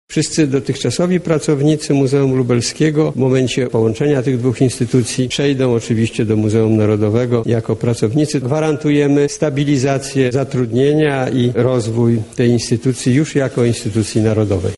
Minister Kultury i Dziedzictwa Narodowego złożył także ważną deklarację co do działalności placówki.